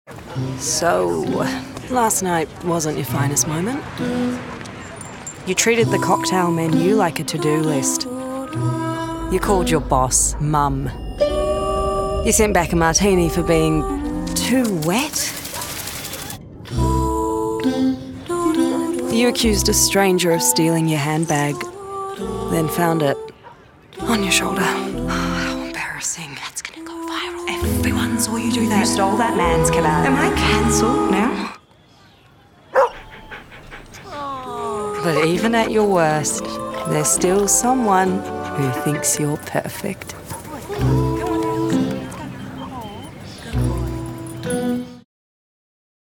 Young Adult, Adult
australian | natural
new zealand | natural
warm/friendly